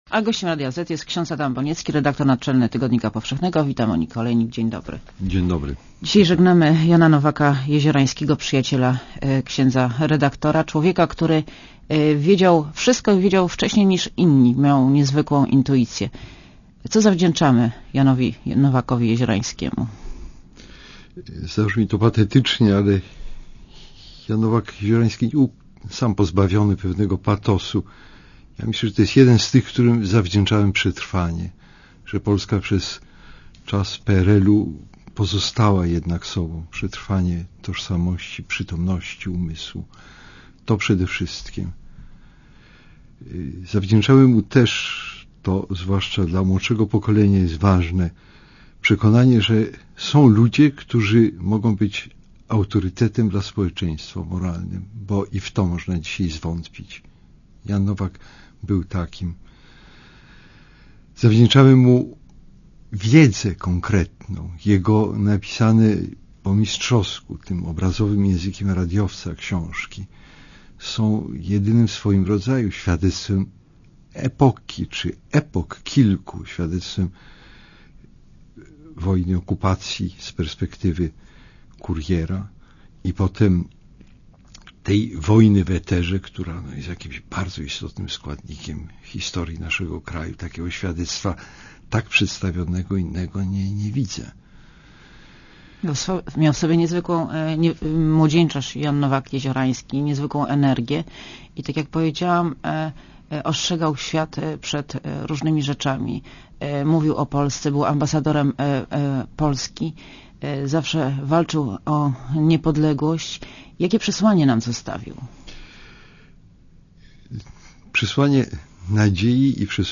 Posłuchaj wywiadu A gościem Radia Zet jest ks .